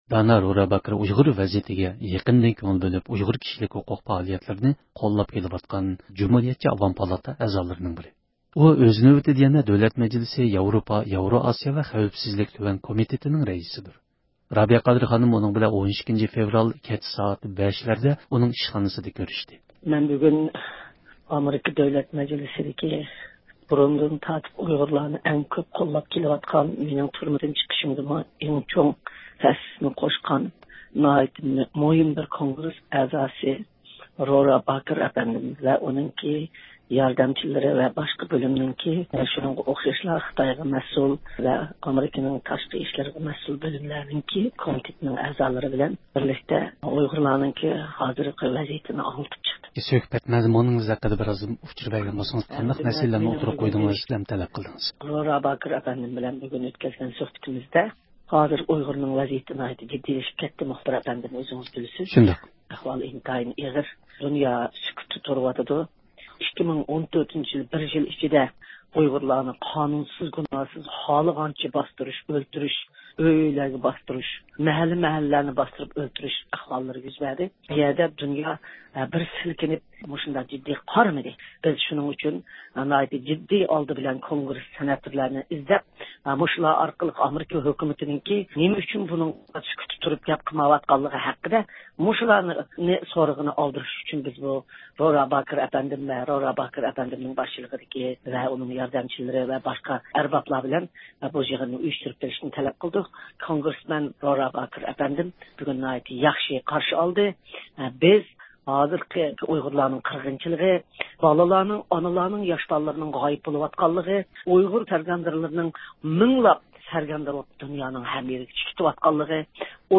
بۇ مۇناسىۋەت بىلەن بىز رابىيە قادىر خانىمنى زىيارەت قىلدۇق.